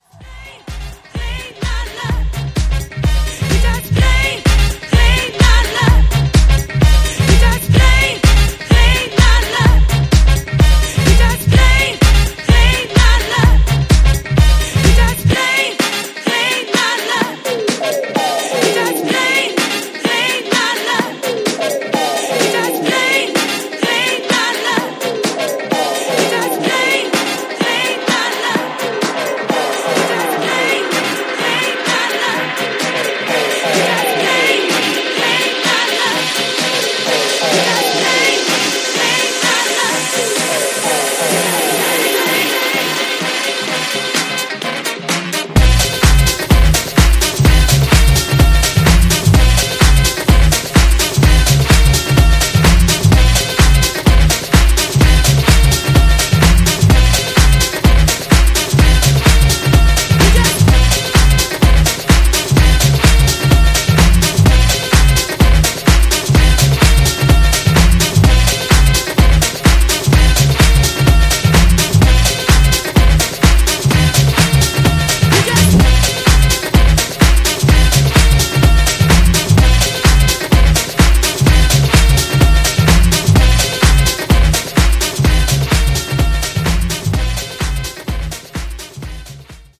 ジャンル(スタイル) DISCO HOUSE / EDITS